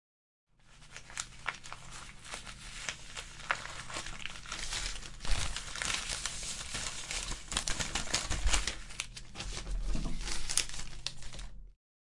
最后的音频 " 37 纸张与纸条
描述：一张纸打衣服
Tag: 衣服 纸张 击球 声音